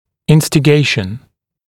[ˌɪnstɪ’geɪʃn][ˌинсти’гейшн]наущение, побуждение, подстрекательство